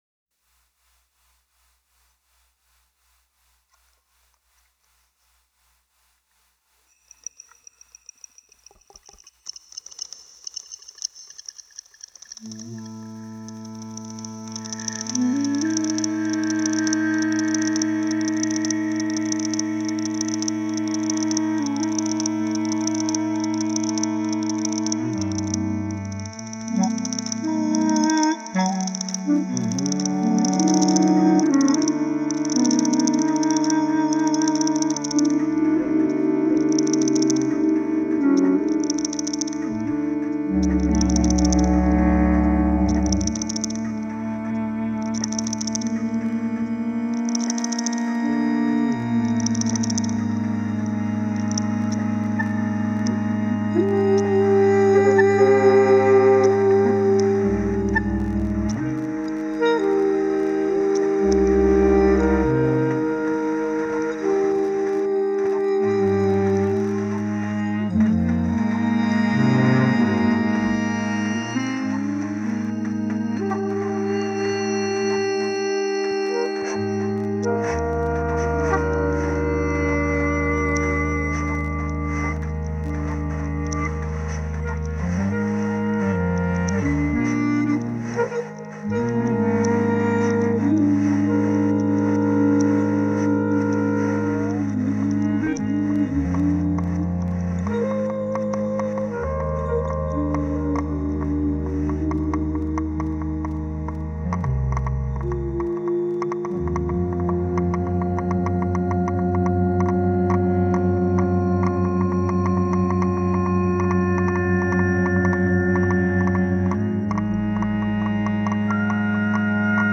bassoon/clarinet/sax
guitars/keyboard/vocals
drums/percussion